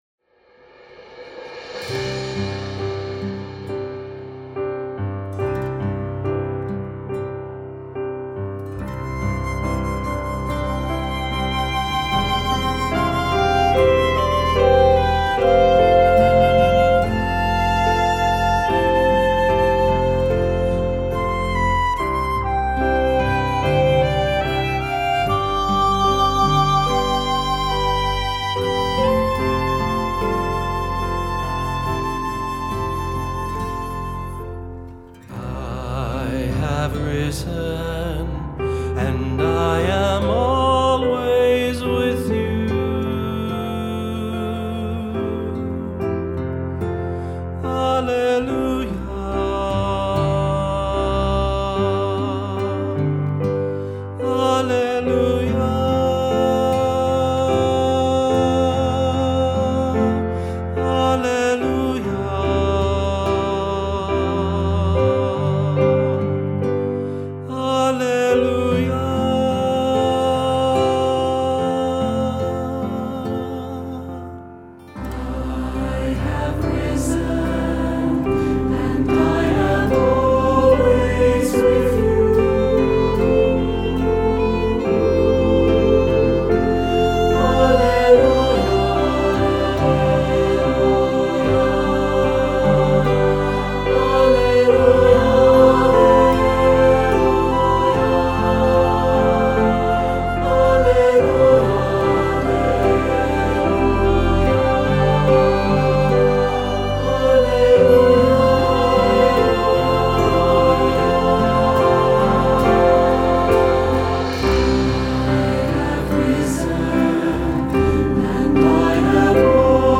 Voicing: SATB; Unison